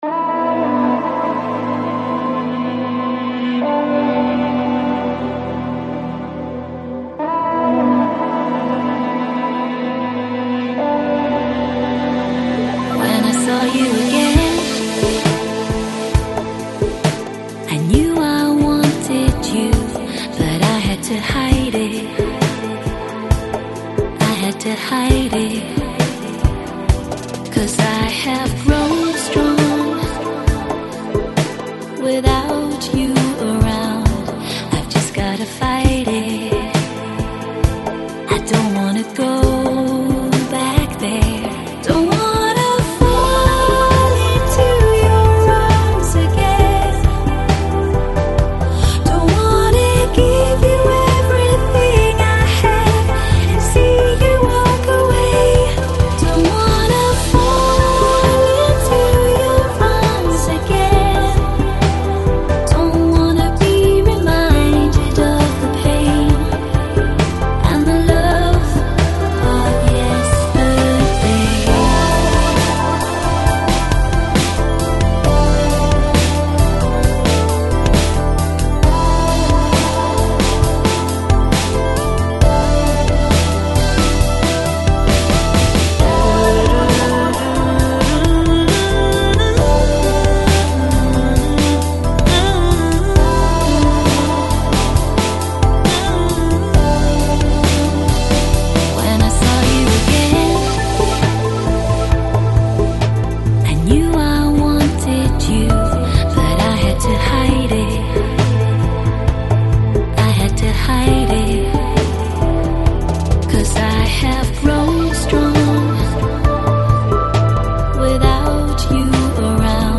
Жанр: Lounge, Chill Out, Downtempo, Balearic